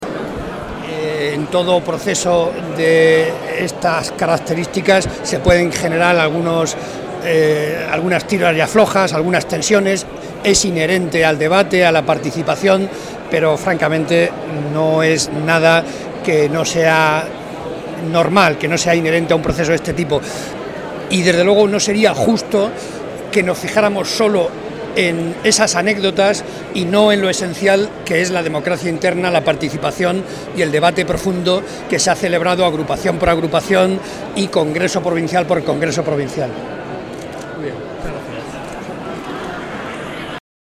Así lo ha asegurado Barreda en declaraciones a los medios al inicio del Congreso socialista, donde ha destacado que es «muy positivo» que haya habido un proceso democrático interno en el partido, así como «mucha participación y mucho debate».